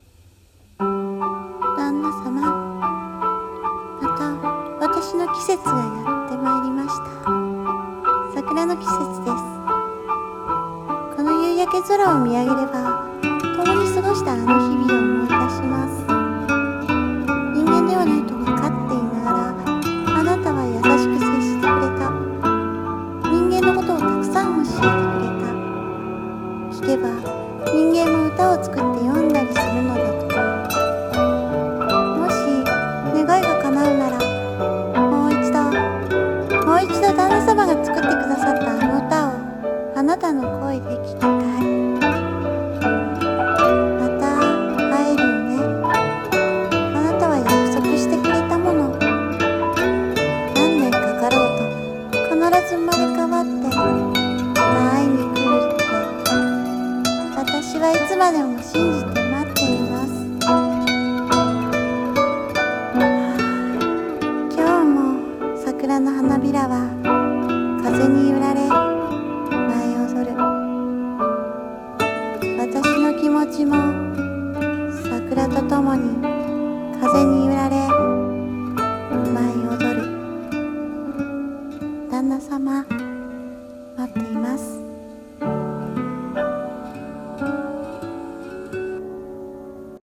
】【声劇】桜の精 【和風】 。